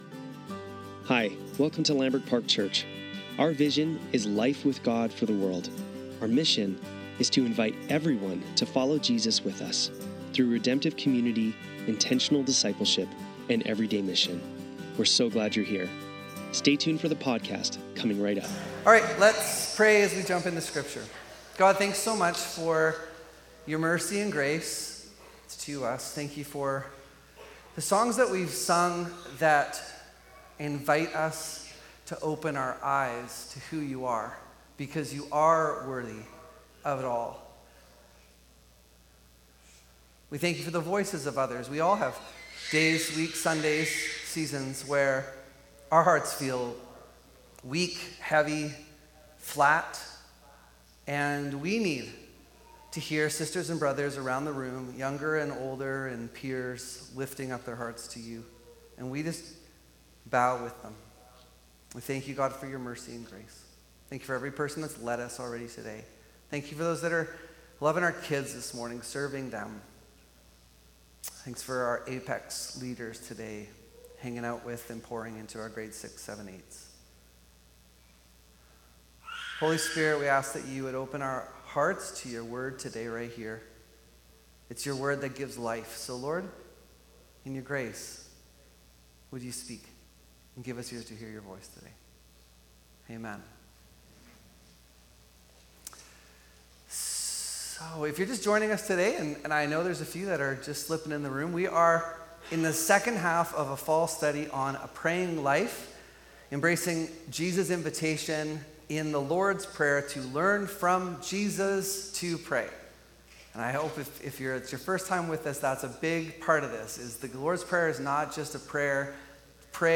Lambrick Sermons | Lambrick Park Church